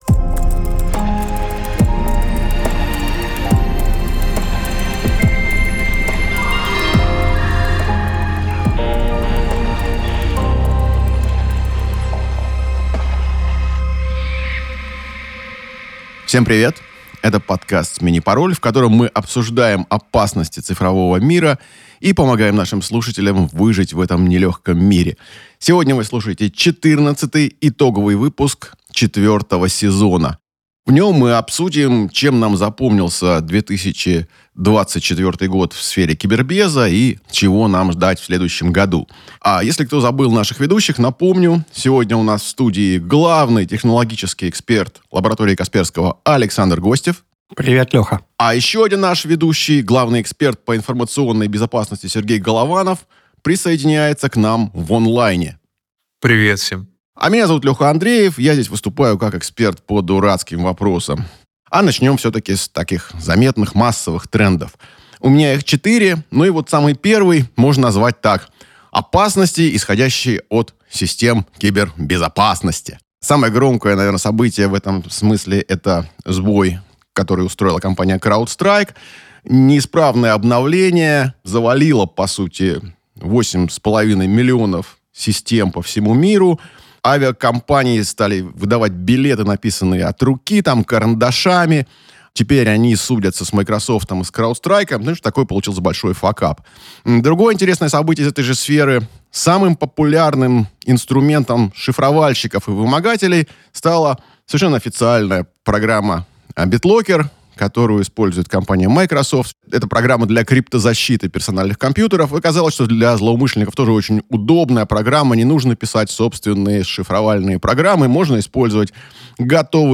Документально-разговорный подкаст о кибербезопасности.